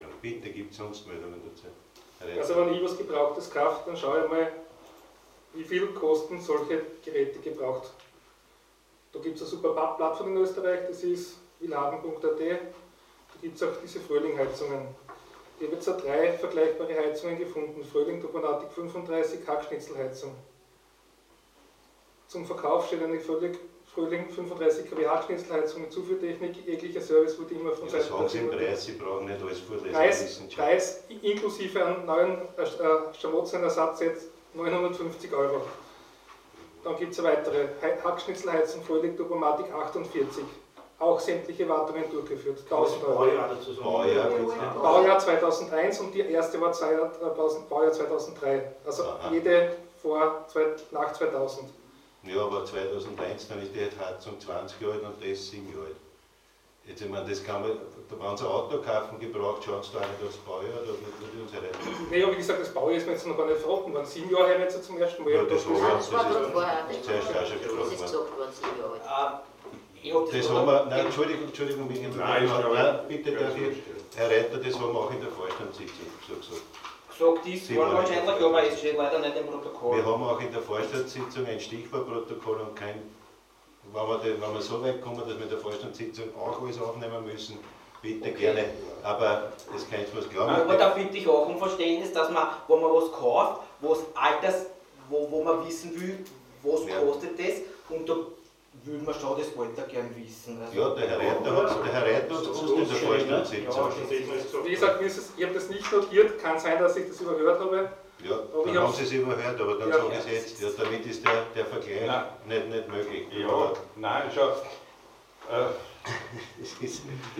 Zu einer mehr als fragwürdigen Abstimmung über den Ankauf einer gebrauchten Hackschnitzelheizung kam es in der Gemeinderatssitzung vom 16. September.
In der nachfolgenden Diskussion stellte der Bürgermeister klar, dass ein Vergleich dieser Angebote nicht möglich sei, da die Anlage des ÖVP-Ortsparteiobmanns mit einem Alter von lediglich 7 Jahren wesentlich jünger ist und das den höheren Preis rechtfertige (Nachzuhören